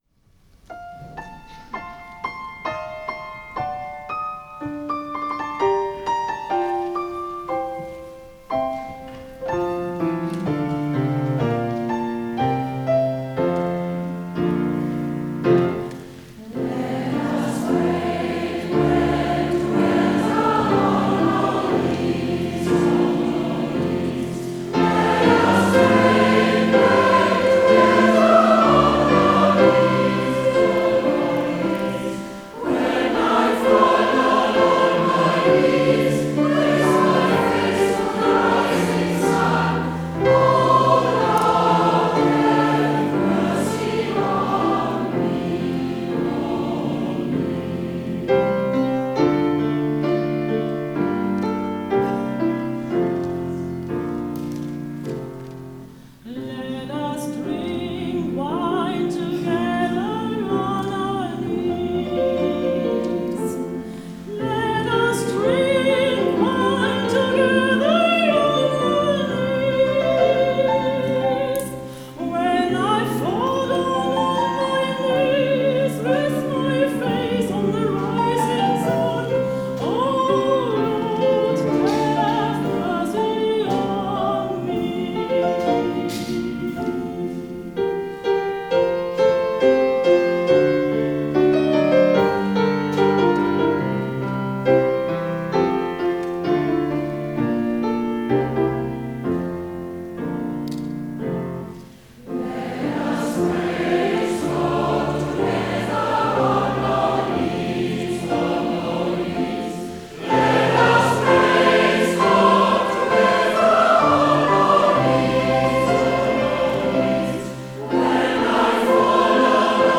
wie auch das Spriritual „Let Us Break Bread Together“
Sopran
Hier wird in sehr gefühlvoller Weise der Wunsch nach unmittelbarer Gemeinschaft spürbar,